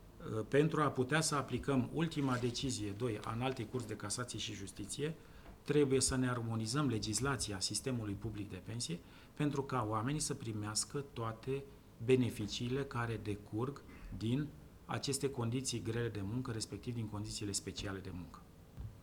voce-baciu.wav